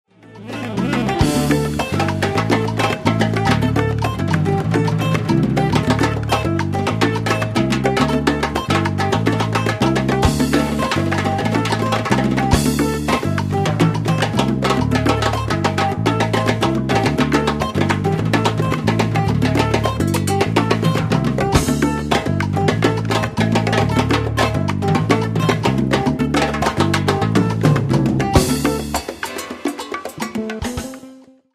Afro-Caribbean music.
timbales